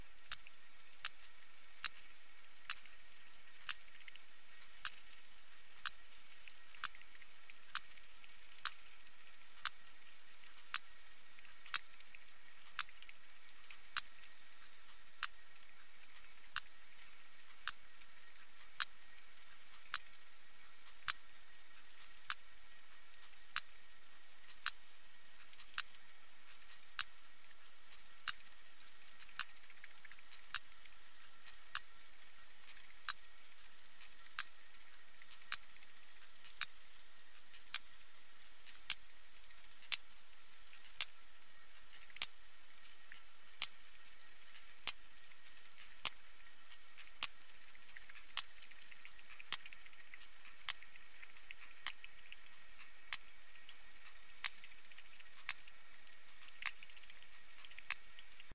На этой странице собраны их уникальные вокализации: от низкочастотных стонов до резких щелчков эхолокации.
Звук щелчков кашалота при поиске добычи слышен за 16 километров